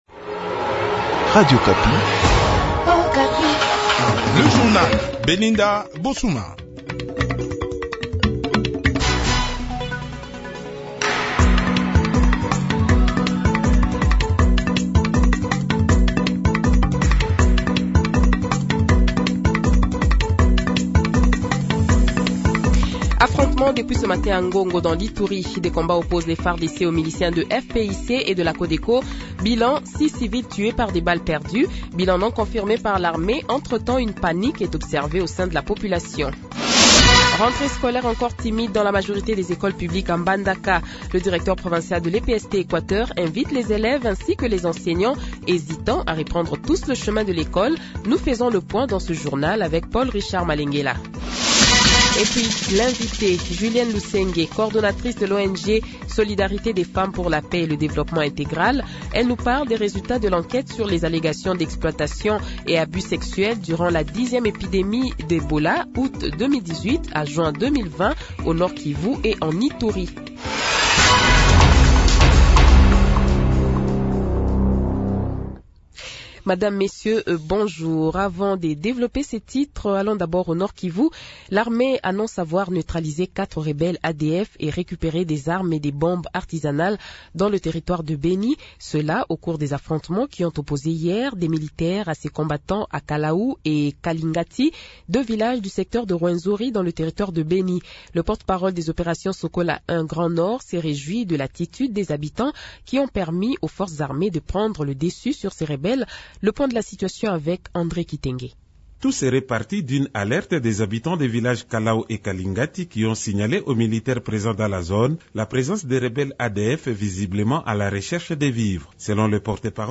Le Journal de 12h, 06 Octobre 2021 :